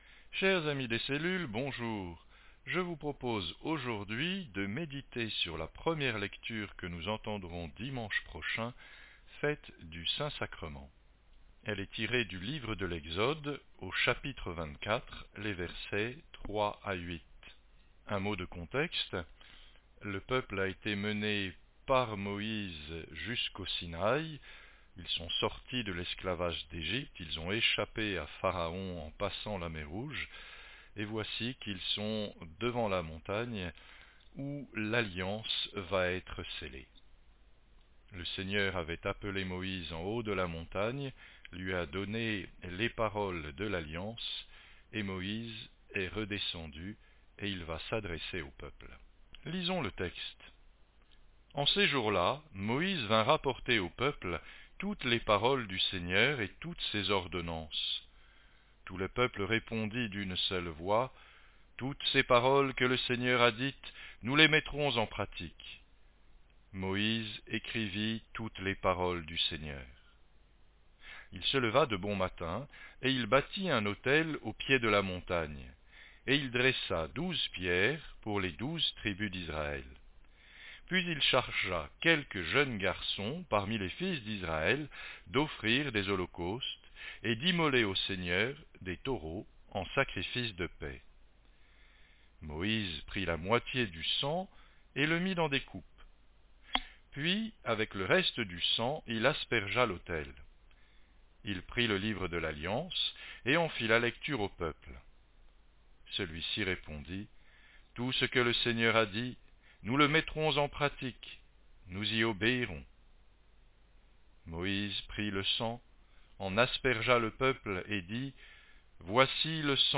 Enseignements pour les cellules